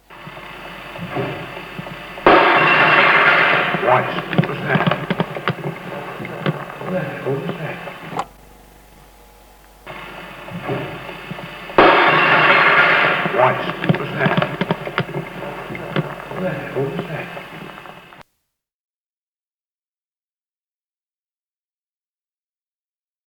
11 Exploding cup, Charlton House, Lond.mp3